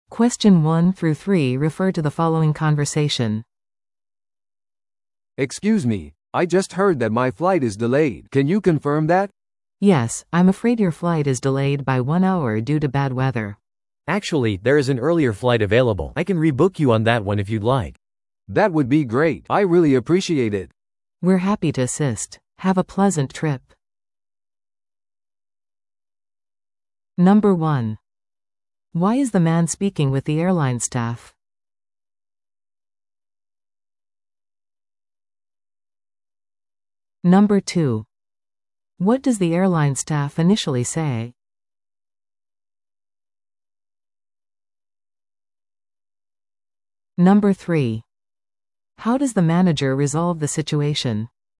TOEICⓇ対策 Part 3｜フライトの遅延と代替便の提案 – 音声付き No.32
No.1. Why is the man speaking with the airline staff?